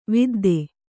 ♪ vidde